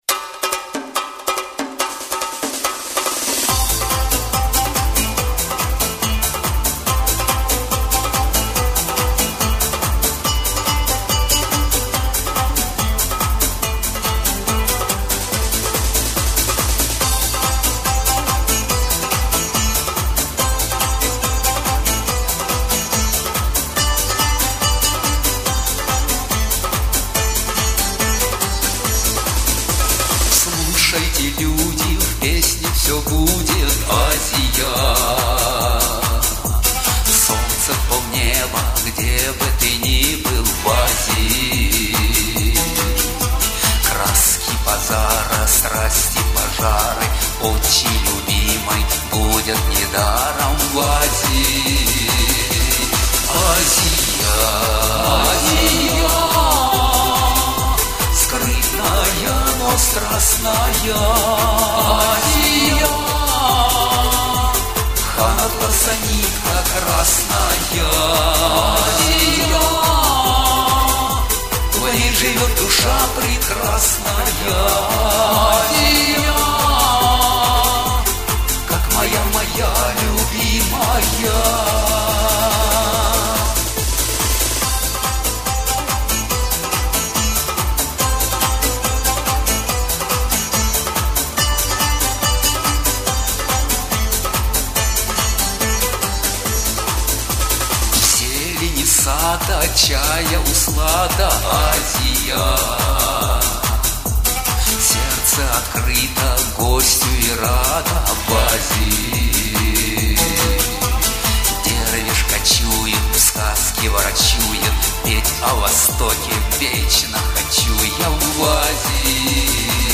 Комментарий инициатора: Приглашаю любителей восточной песни.